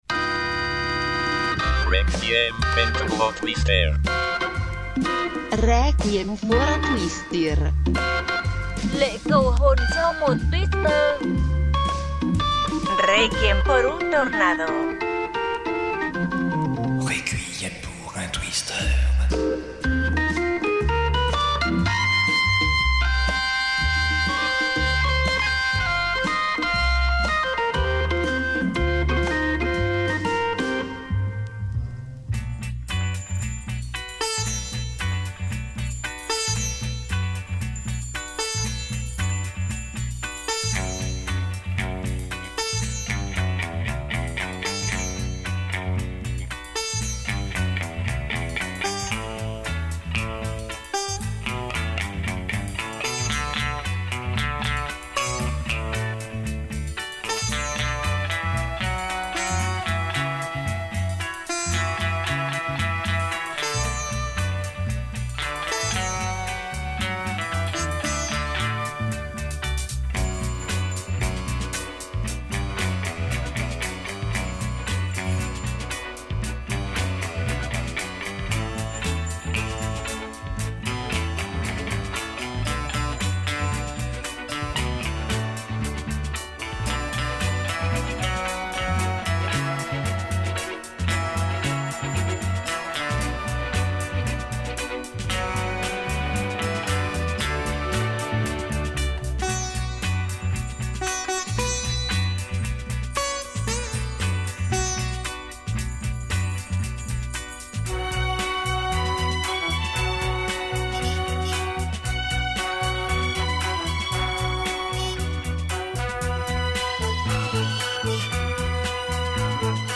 Type Mix Éclectique